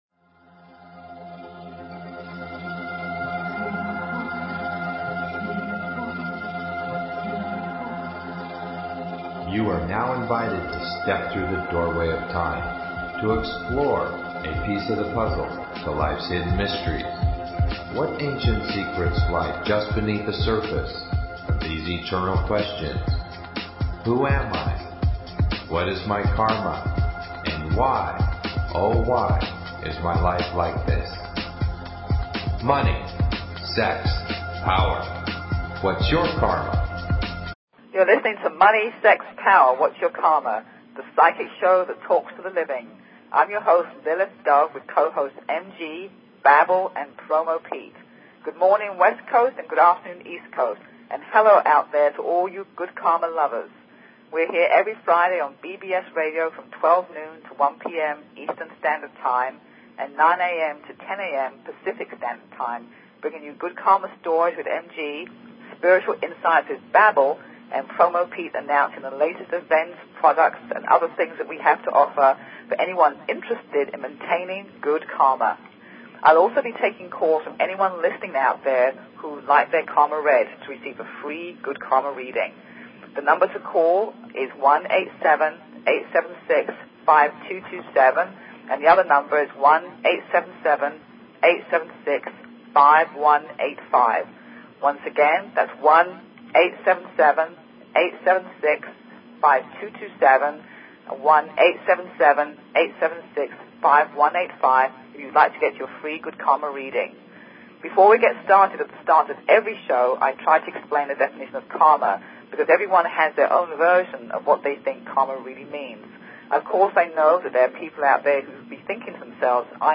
Talk Show Episode, Audio Podcast, Money_Sex_Power_Whats_Your_Karma and Courtesy of BBS Radio on , show guests , about , categorized as
LIVE call-in Karma Readings